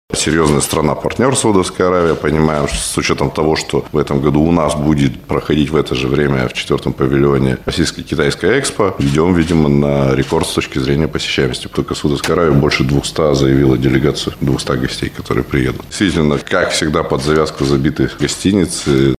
Об этом заявил министр инвестиций и развития Свердловской области Дмитрий Ионин на пресс-конференции «ТАСС-Урал».